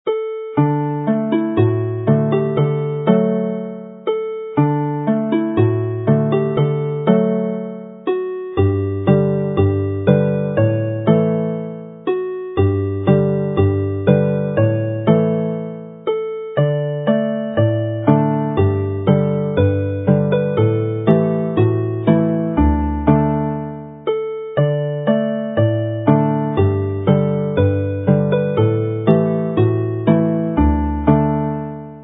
Triban syml yn D - alaw sylfaenol y gân
Basic Triplet in D with each line repeated